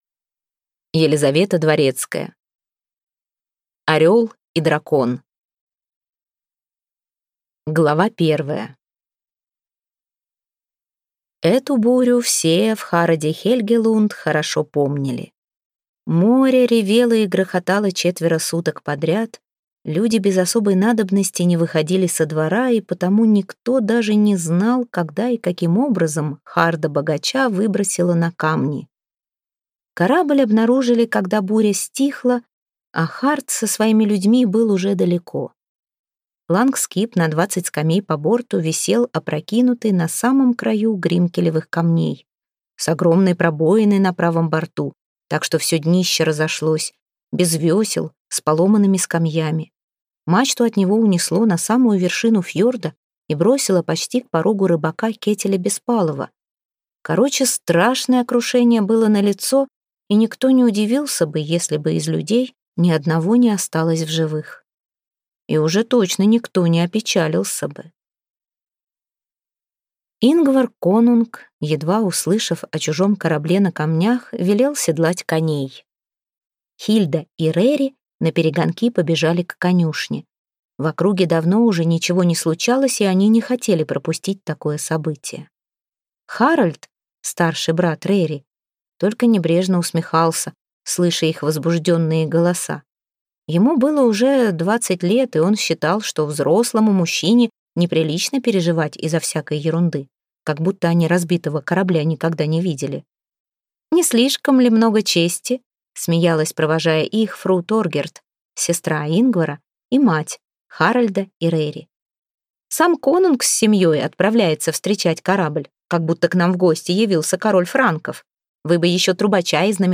Аудиокнига Орел и Дракон | Библиотека аудиокниг